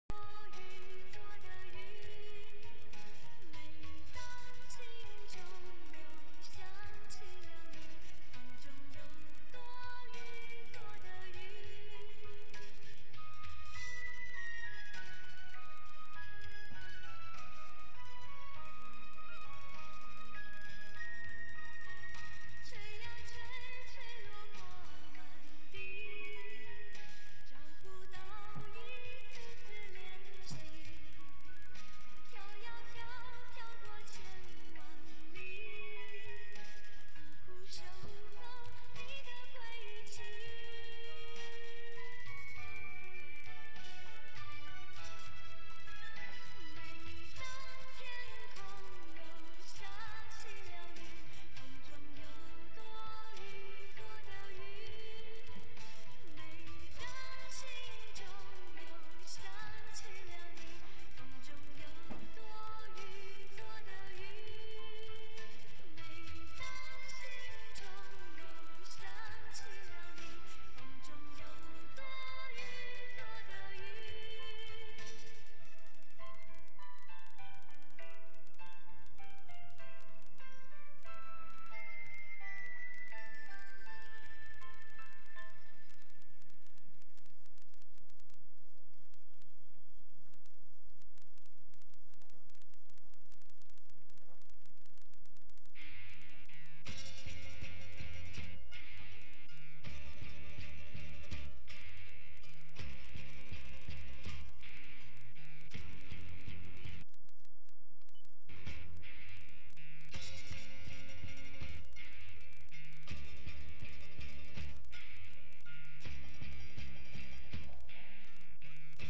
附件是我用命令./encode -v video.264 -I 1 -y 2 - a audio.aac 通过mic 录下来的，这个audio.aac是有破音问题的。